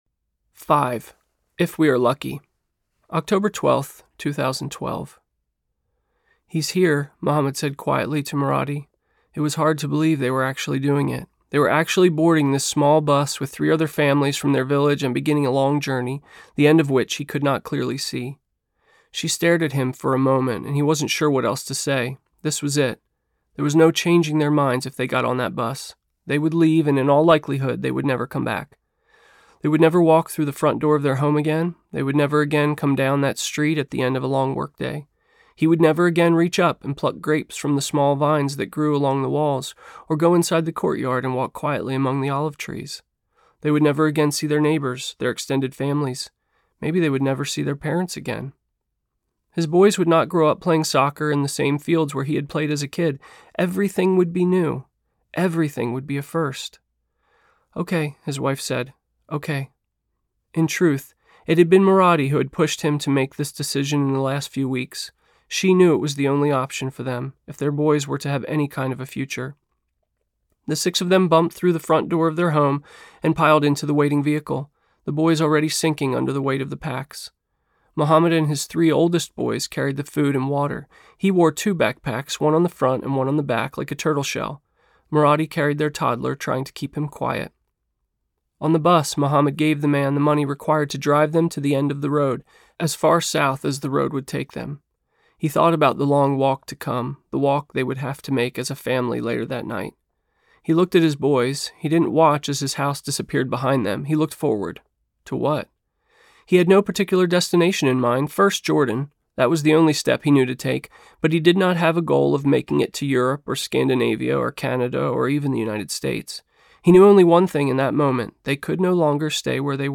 Once We Were Strangers Audiobook
4 Hrs. – Unabridged